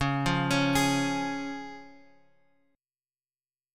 C# chord {x 4 3 x 2 4} chord
Csharp-Major-Csharp-x,4,3,x,2,4-8.m4a